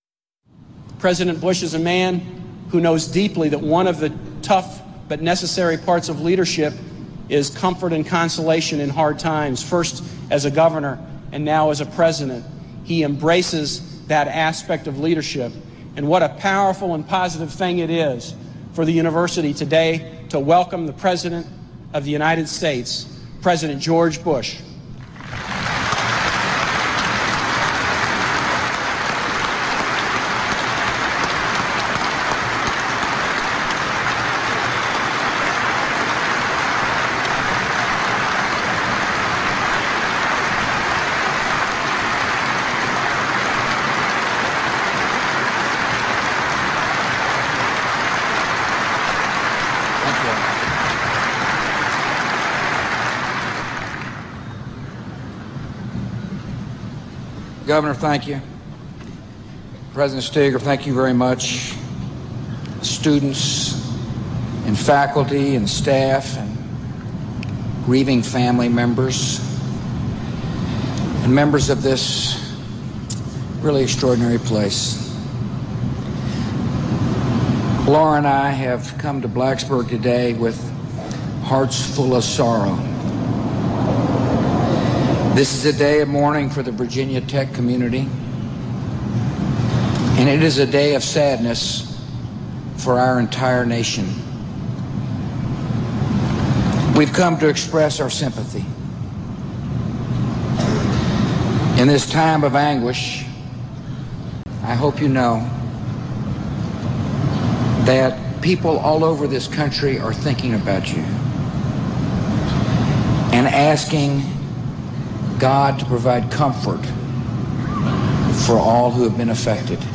U.S. President George W. Bush speaks at Virginia Tech a day after the shootings